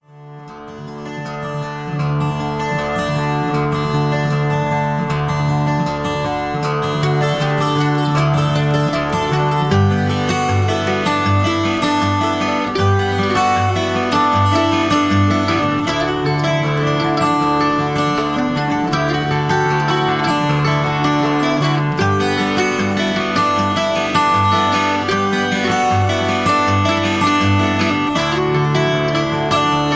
Tags: backing tracks, karaoke, sound tracks, rock and roll